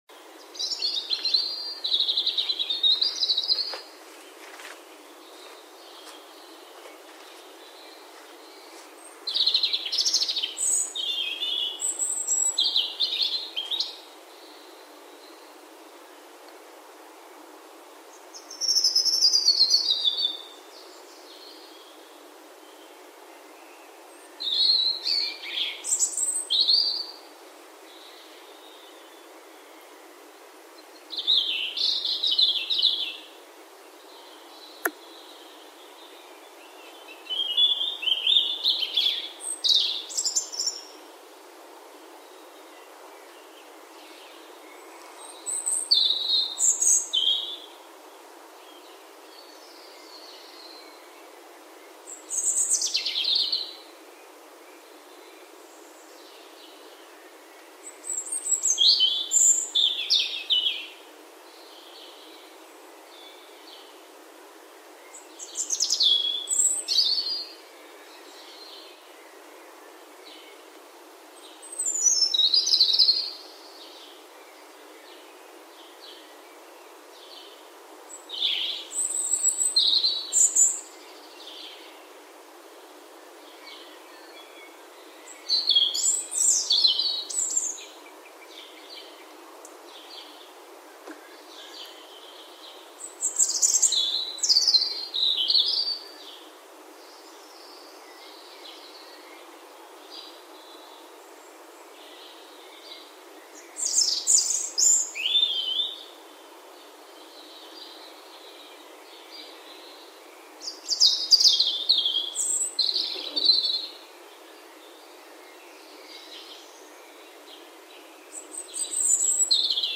beautiful-dawn-chorus-birdsong-west-sussex-uk.mp3